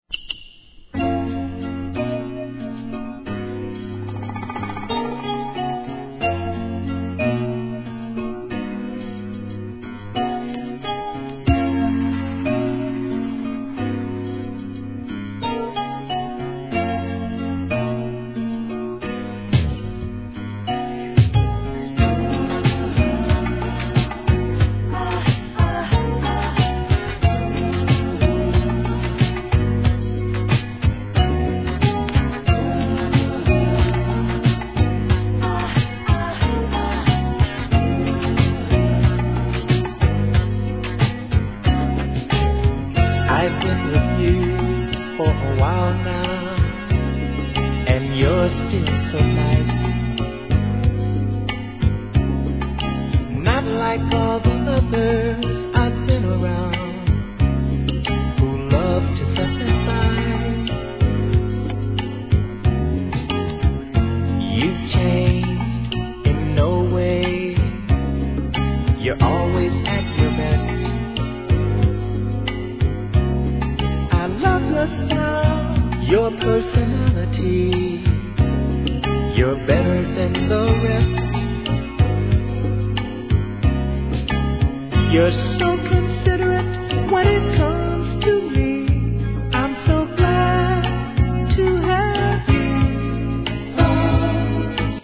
SOUL/FUNK/etc...
素晴らしいメロ〜・ソウル!!